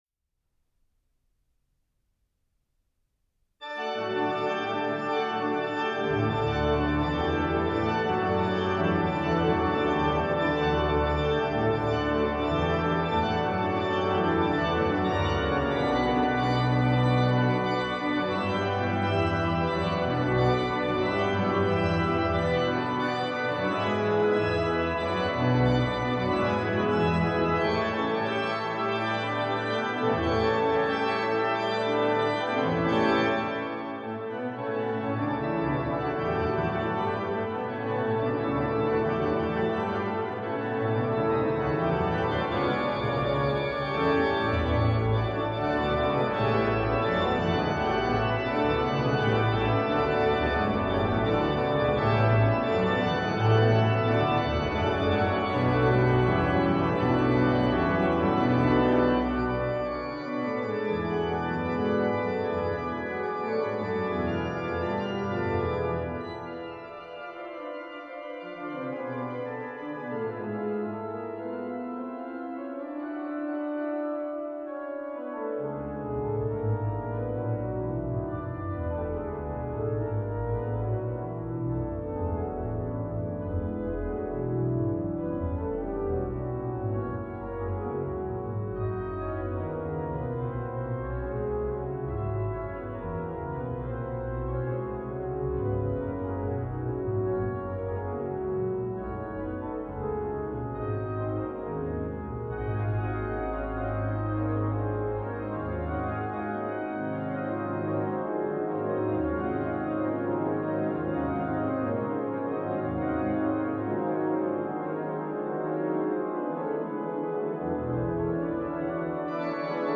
Dit instrument heeft 12 geluidskanalen, 51 registers, 3 klavieren en 4 bibliotheekregisters.